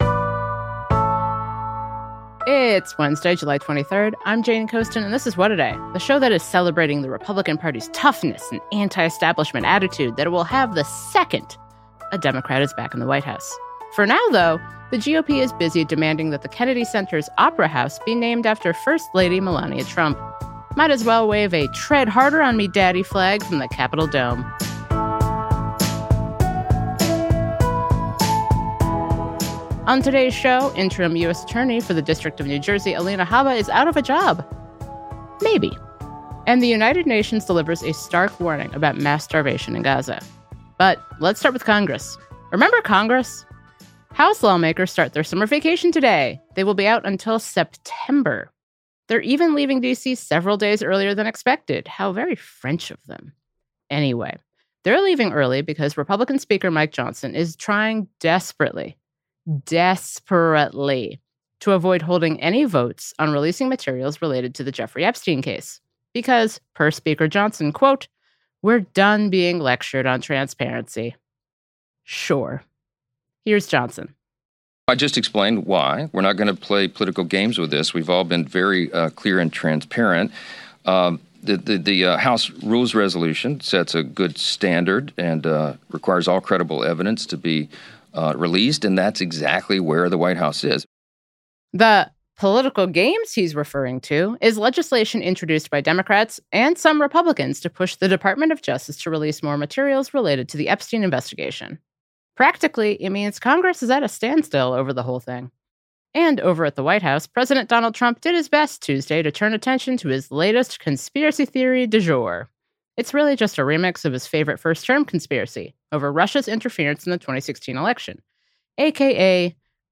California Democratic Rep. Robert Garcia, ranking member on the House Oversight Committee, talks about what Democrats are doing to keep the Trump administration in check.